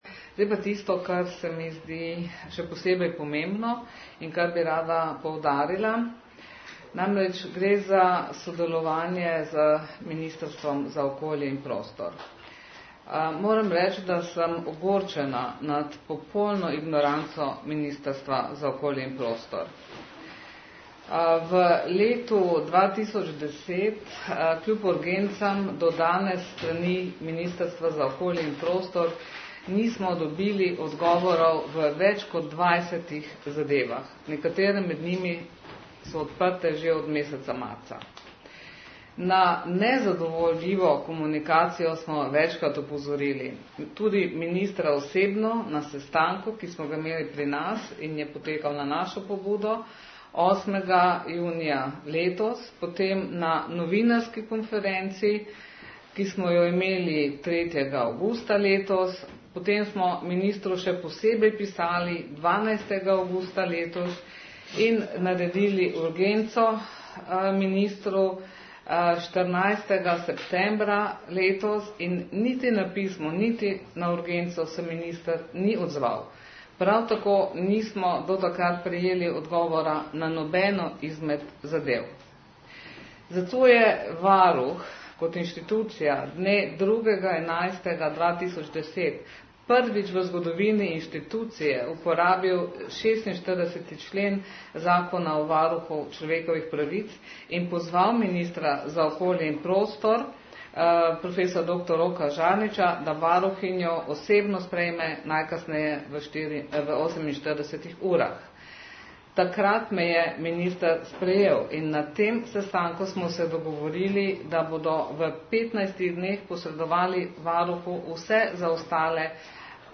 Varuhinja človekovih pravic dr. Zdenka Čebašek - Travnik je ob robu današnje novinarske konference, posvečene pravicam otrok in lansiranju nove spletne strani Varuha za otroke in mladostnike, izrazila ogorčenje nad nesodelovanjem Ministrstva RS za okolje in prostor.